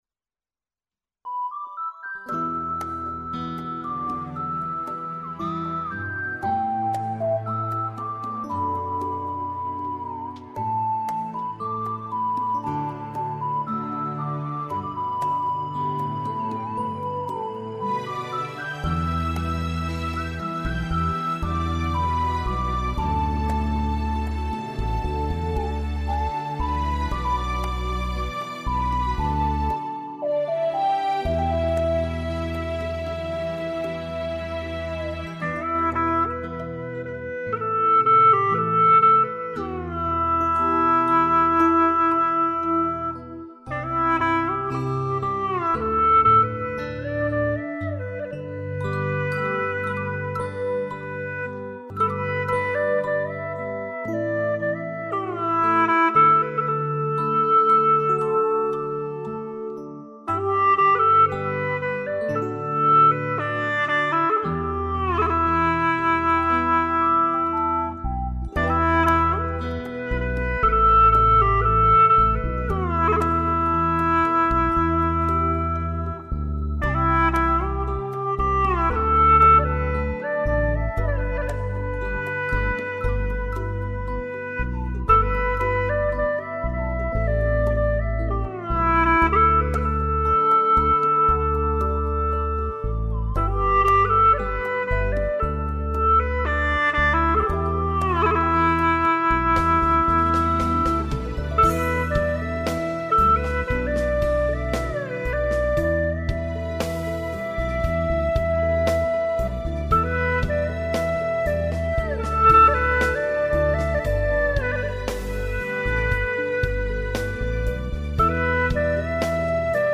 调式 : G 曲类 : 流行